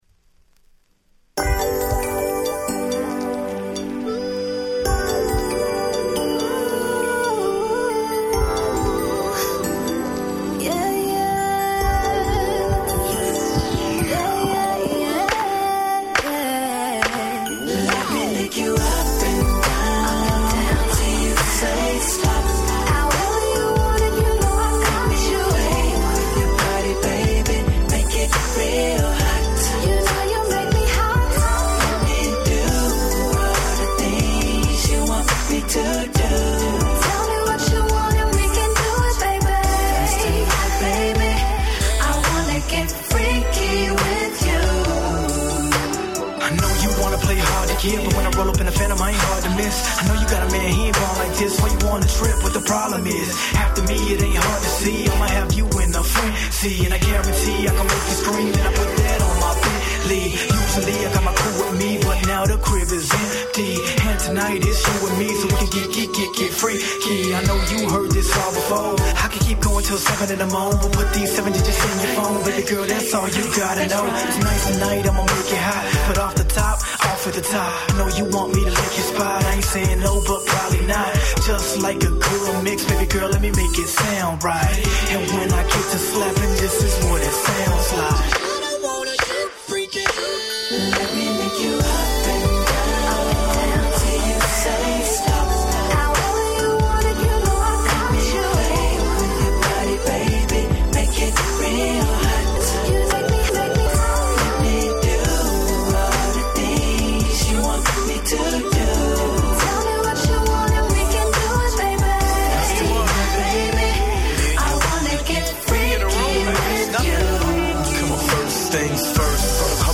09' Smash Hit Hip Hop !!